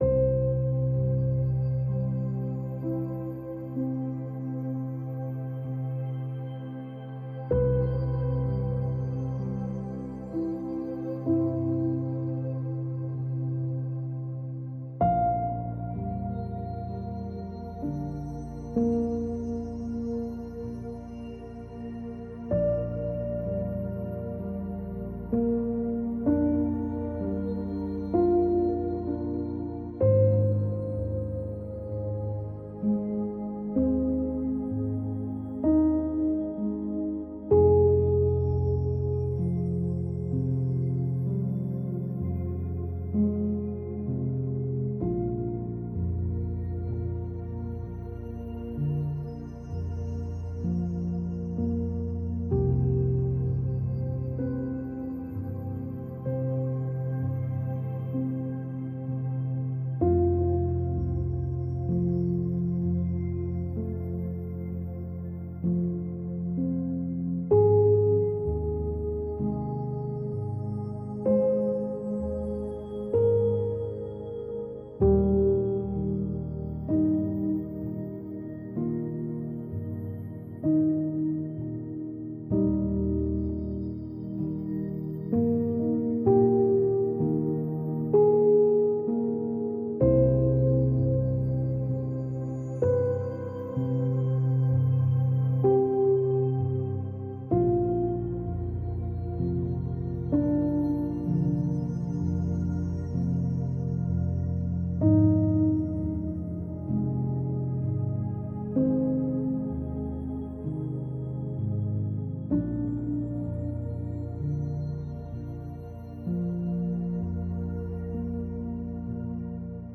Genre: ambient, newage.